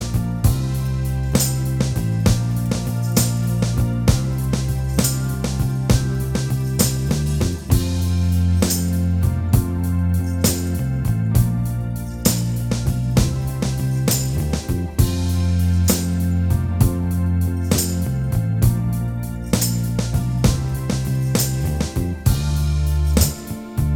Minus Bass Guitar Rock 2:58 Buy £1.50